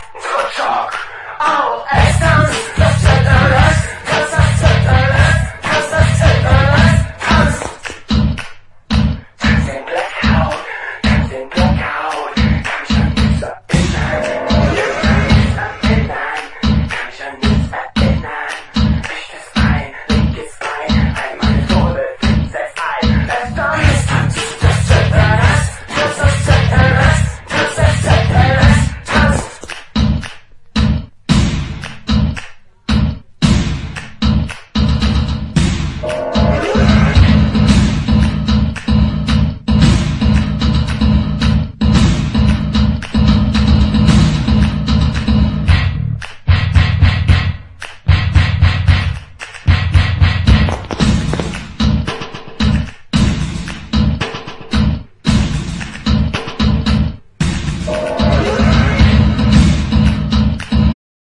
現行サイケデリック・ガレージ・パンク！